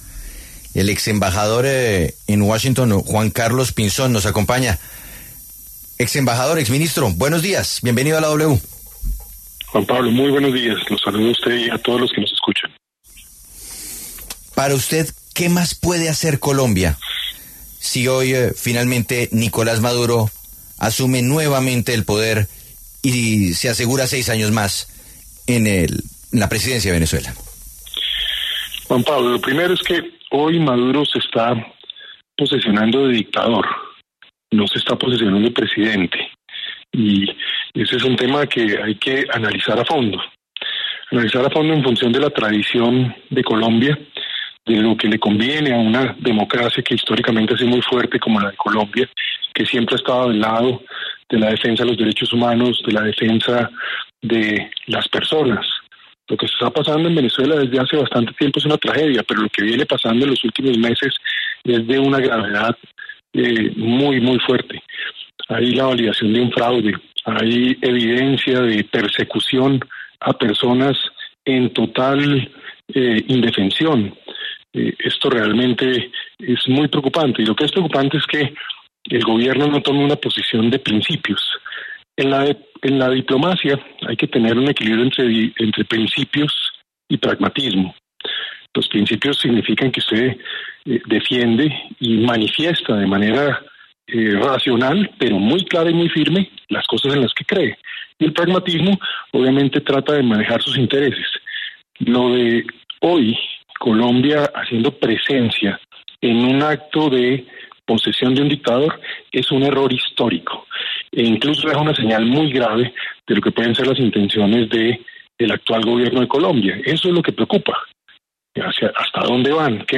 Juan Carlos Pinzón, exembajador de Colombia en Estados Unidos, pasó por los micrófonos de La W y conversó sobre lo que viene para Colombia luego de juramentar un nuevo periodo del Gobierno Maduro.